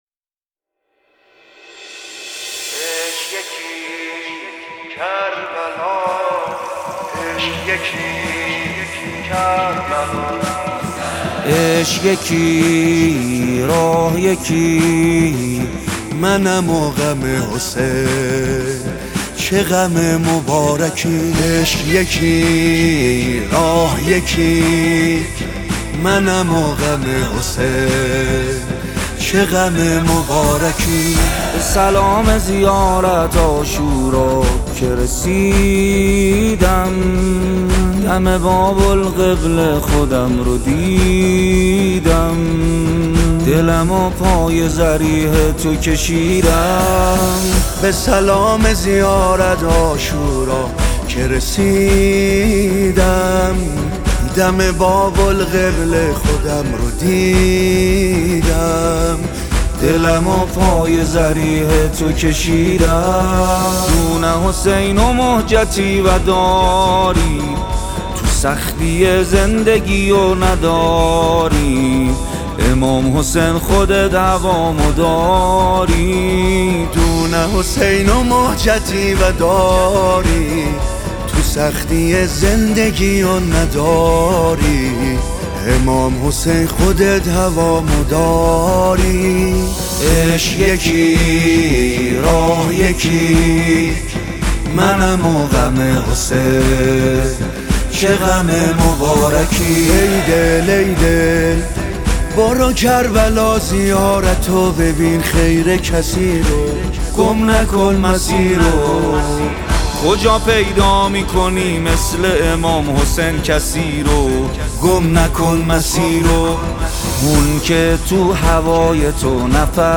نوحه و مداحی